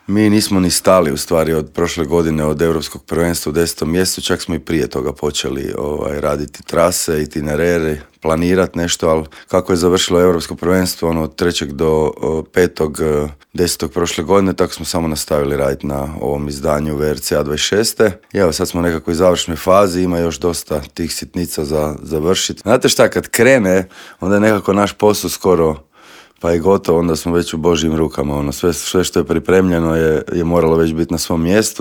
Osvrnuo se u intervjuu Media servisa na značaj utrke, utjecaj na gospodarstvo, stazu i na sve popratne događaje.